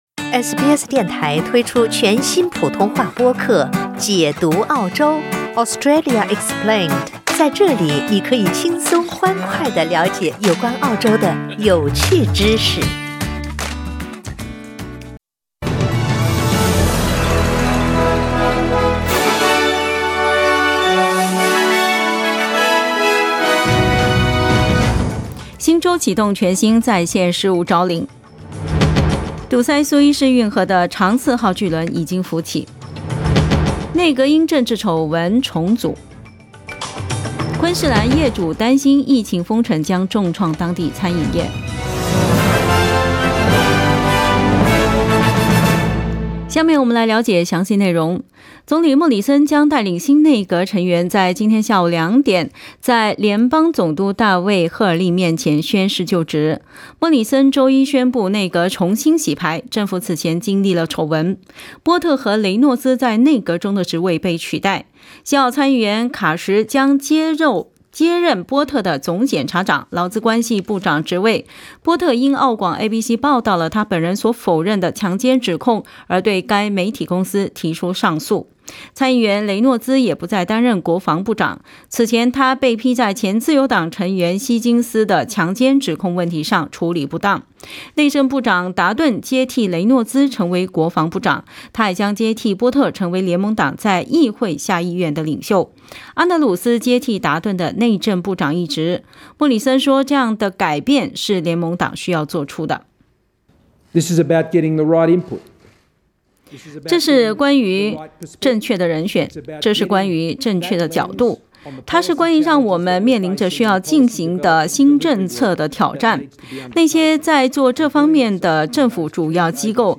SBS 早新聞 （3月31日）